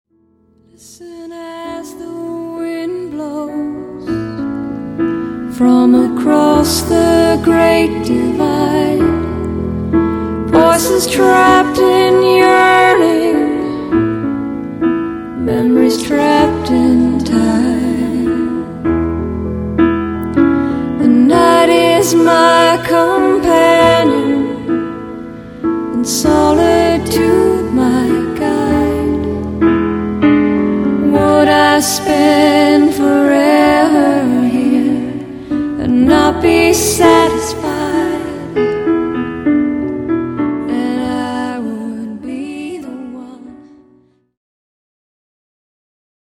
pop-rock - backups only